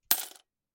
描述：硬币掉落在更多硬币上
Tag: 变化 硬币 金属 叮当 秋天 下降 货币 叮当声 现金 一分钱 硬币 一分钱 四分之一 下降